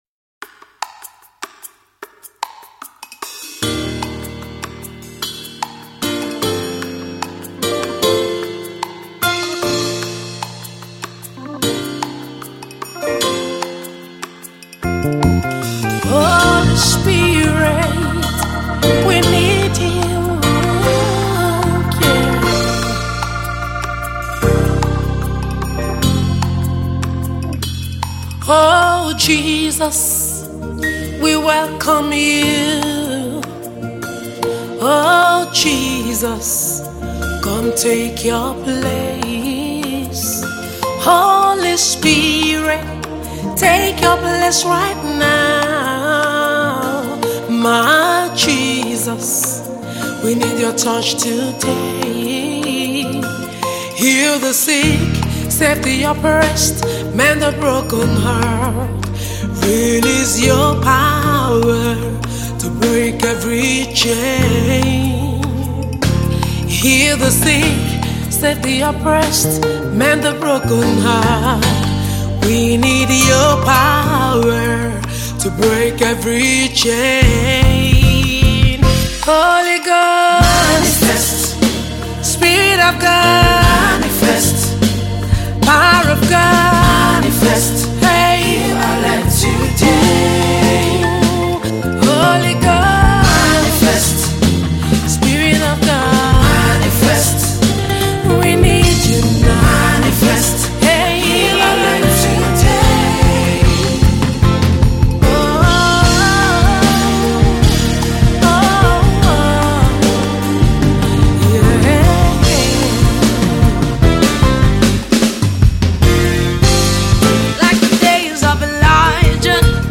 Tags: Gospel Music,